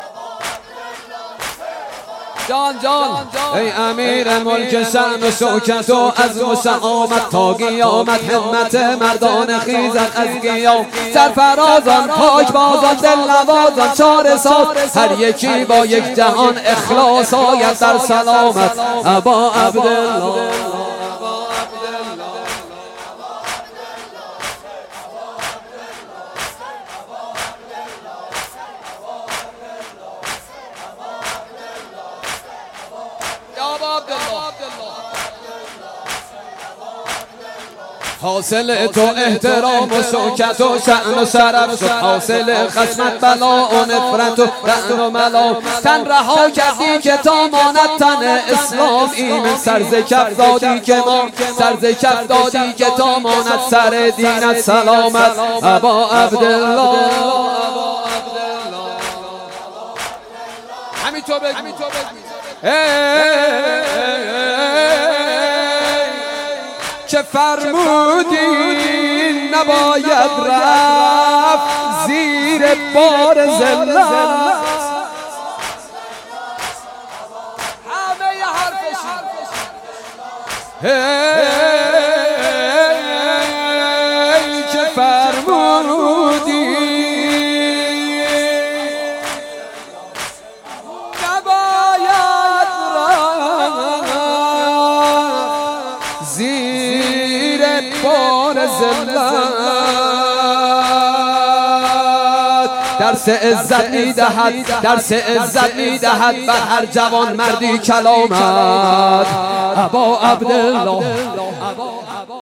حسینیه بیت النبی
مراسم عزاداری ایام اخر صفر-26و27و28 .96.8